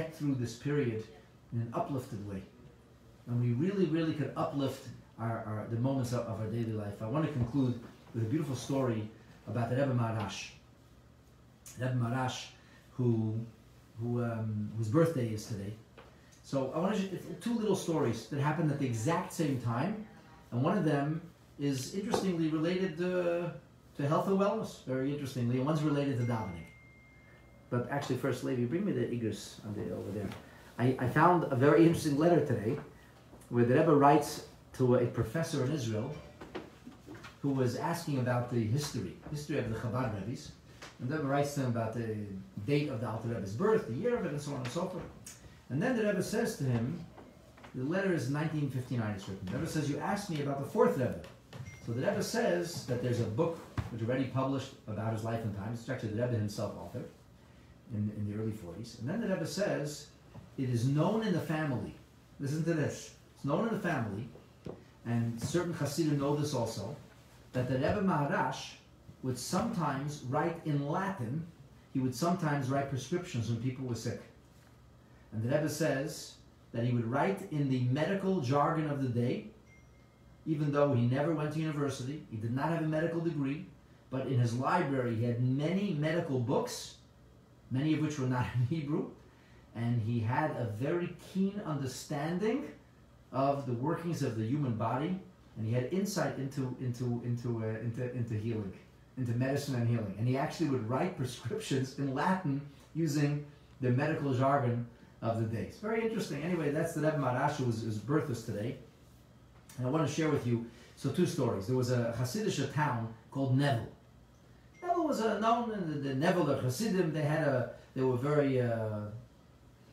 Short Stories Of The Rebbe Maharash - Rosh Chodesh/Bais Iyar Farbrengen 5780